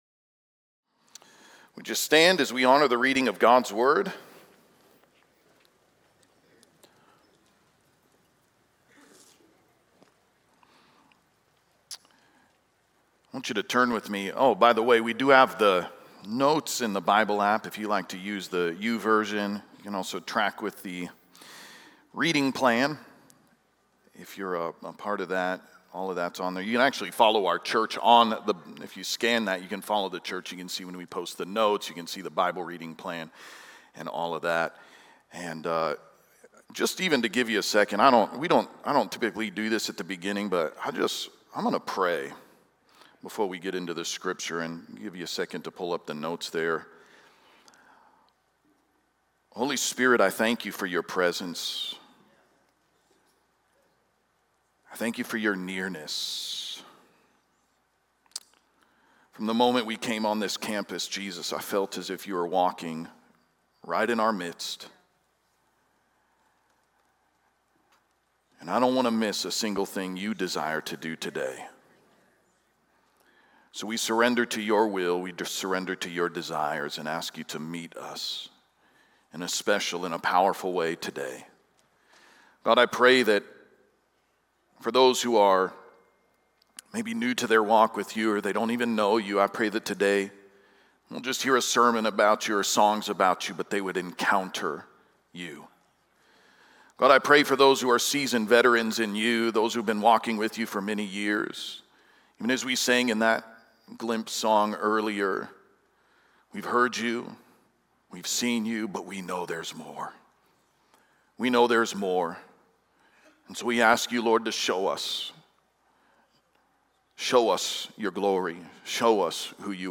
Chosen To Grow | Acts 22:4-16 | Trinity Church